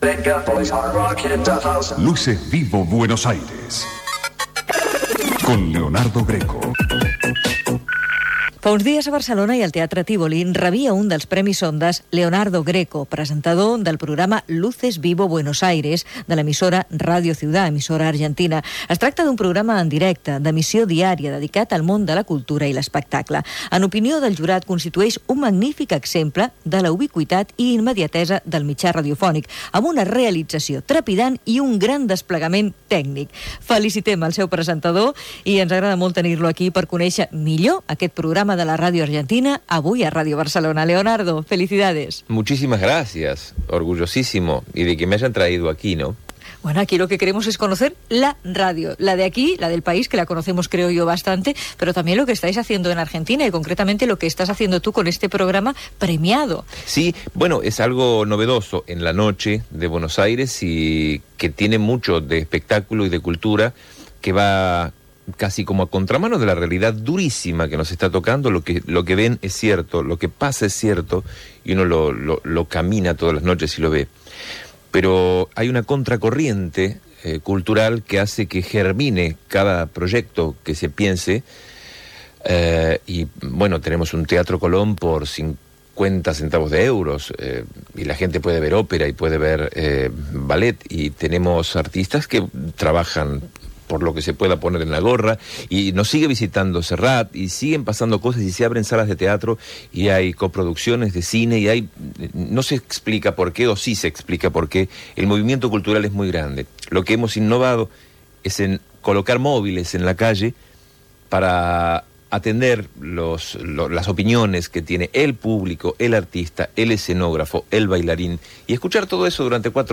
Entrevista
Divulgació